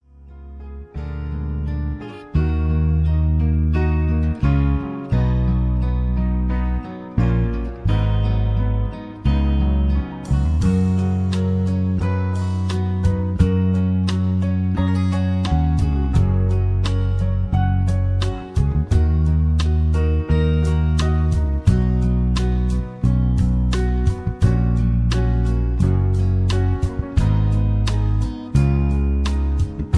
backing tracks
rock and roll, rock, country music